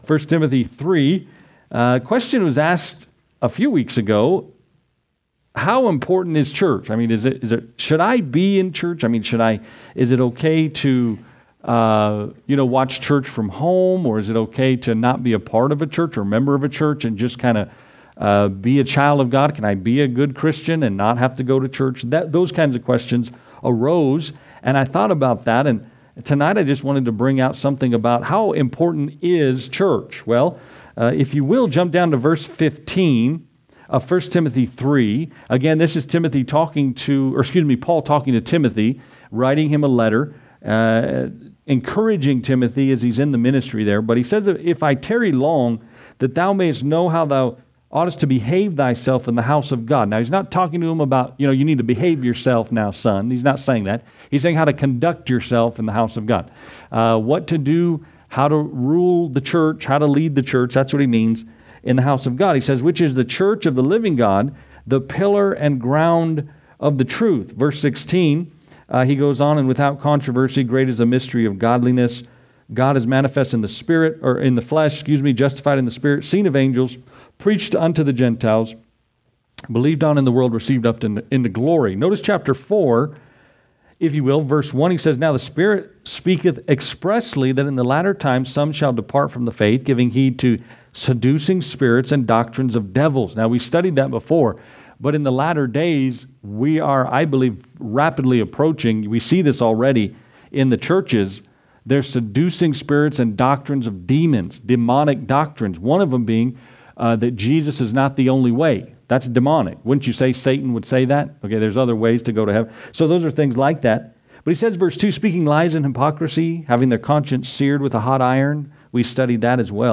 Furthermore, the sermon highlights the church as a crucial environment for mutual encouragement in love and good works , referencing Hebrews 10:24-25 and Psalm 73 to illustrate how corporate worship provides perspective and strengthens faith amidst worldly challenges.